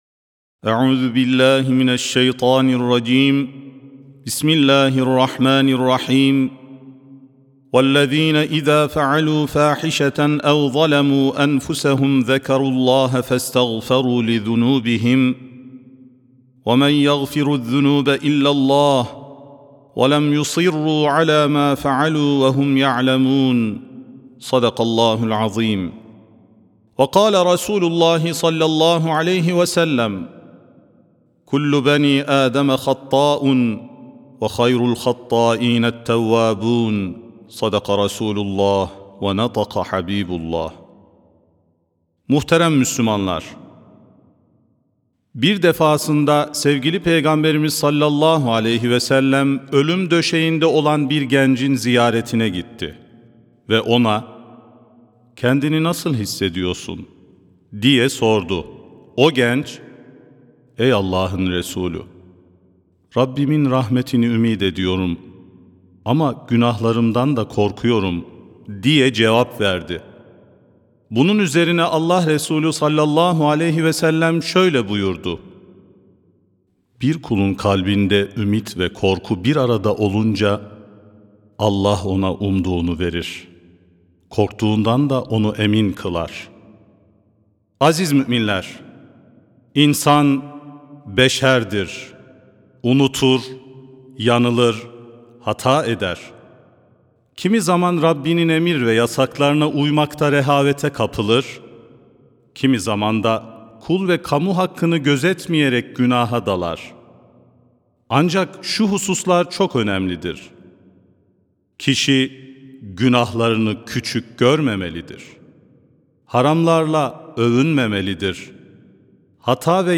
30 Ocak 2026 Tarihli Cuma Hutbesi
Sesli Hutbe (Tövbeye Yönelmek).mp3